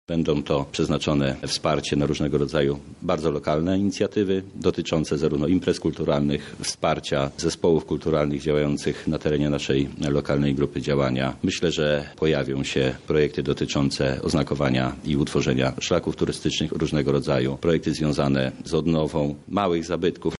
Ponad 2 miliony złotych w ten sposób chce wydać starosta lubelski Paweł Pikula reprezentujący grupę lokalną „Kraina wokół Lublina”.